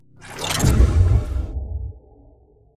flash_blowout.ogg